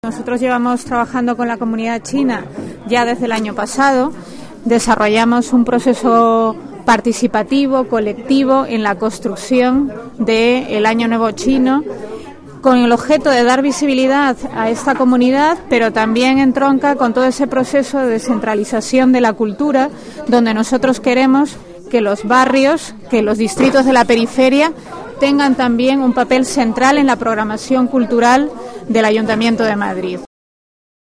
Nueva ventana:Declaraciones de Rommy Arce, concejal presidenta de los distritos de Usera y Arganzuela, sobre la celebración del Año Nuevo Chino